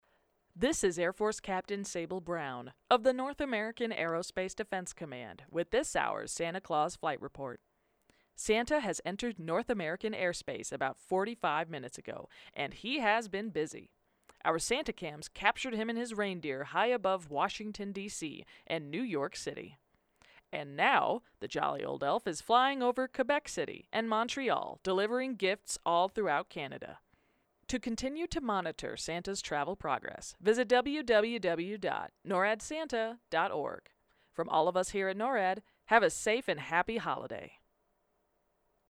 NORAD Tracks Santa radio update to be aired at 9pm MTS on December 24, 2022